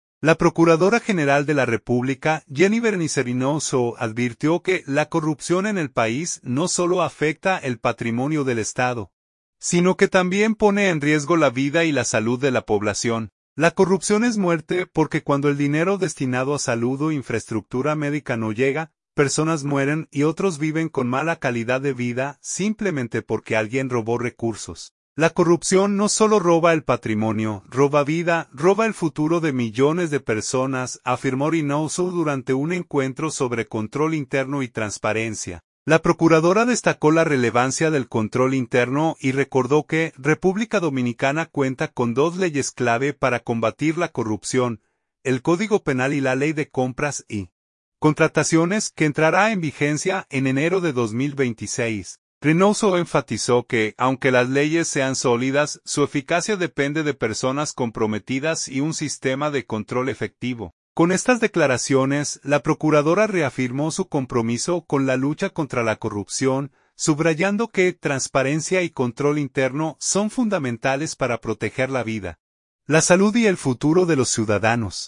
Santo Domingo, RD. — La Procuradora General de la República, Yeni Berenice Reynoso, advirtió que la corrupción en el país no solo afecta el patrimonio del Estado, sino que también pone en riesgo la vida y la salud de la población.